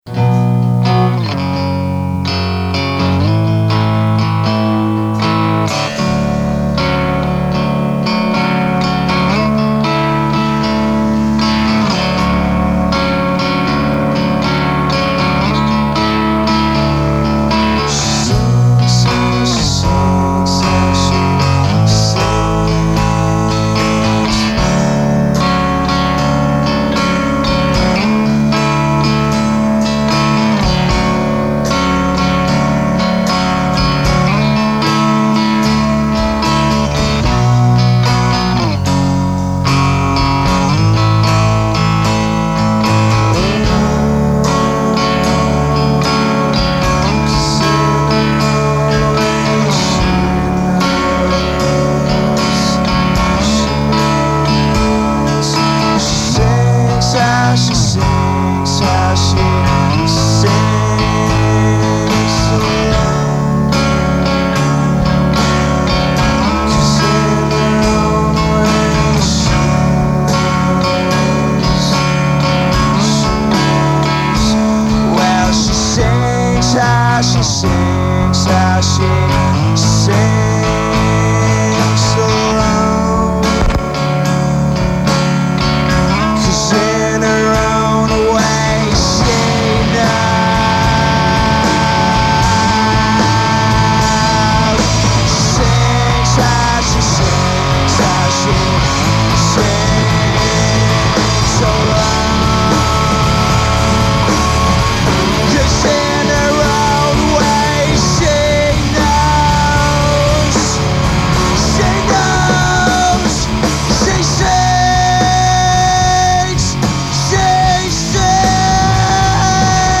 Guitar
Bass
Vocal
Drums
Emo , Indie